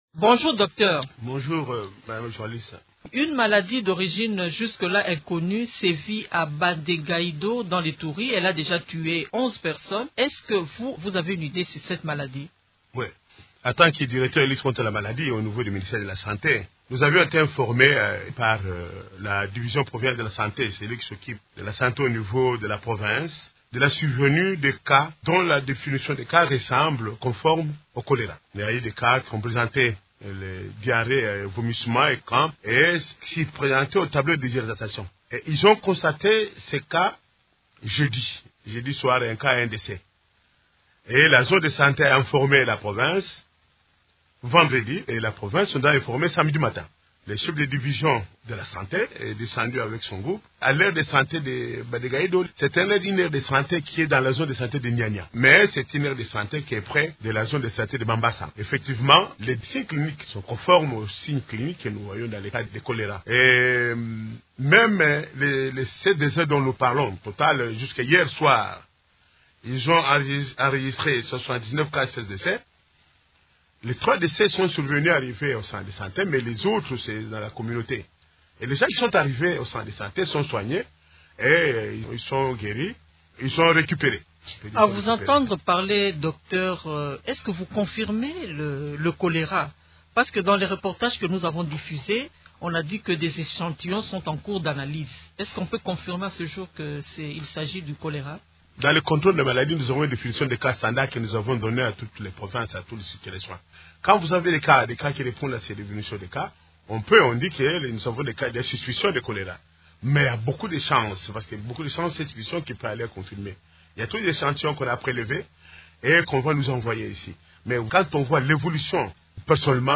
Invité de Radio Okapi lundi 27 février, il annonce qu’un comité de crise a été mis en place au cabinet du ministre de la Santé à Kinshasa pour faire face à cette maladie.